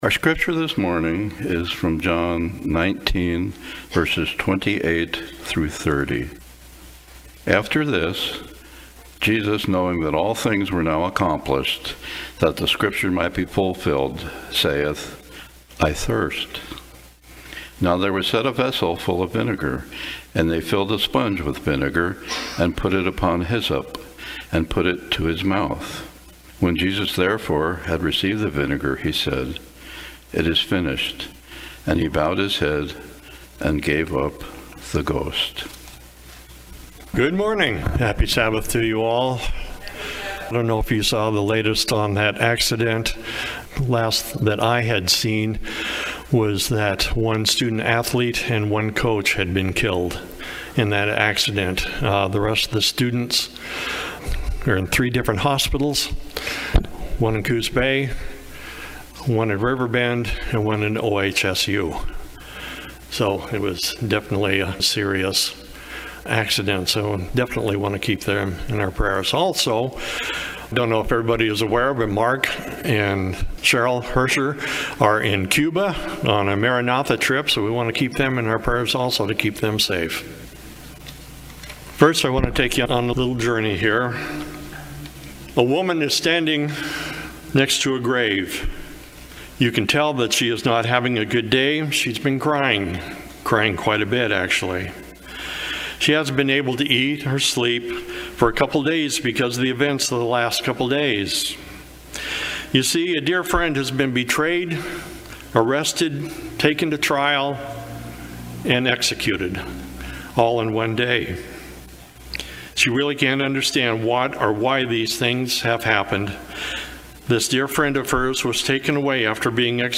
Seventh-day Adventist Church, Sutherlin Oregon
Sermons and Talks